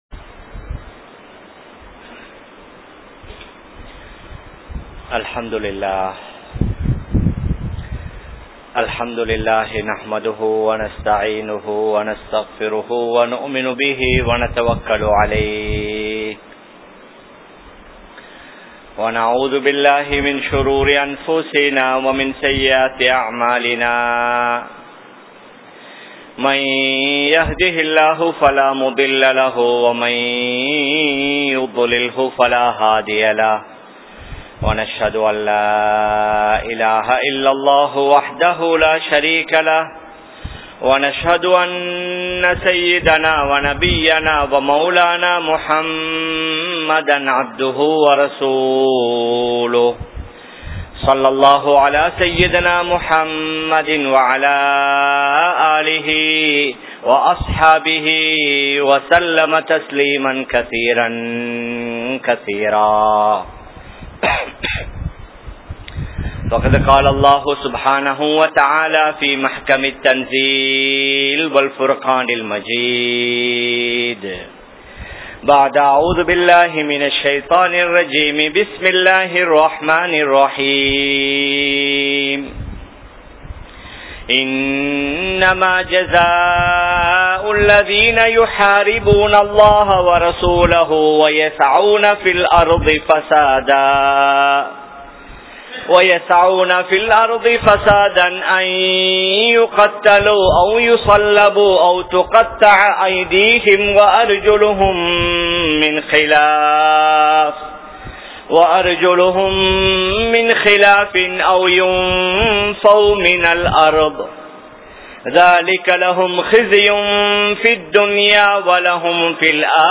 Muslimkale! Ottrumaiyaaha Vaalungal (முஸ்லிம்களே! ஒற்றுமையாக வாழுங்கள்) | Audio Bayans | All Ceylon Muslim Youth Community | Addalaichenai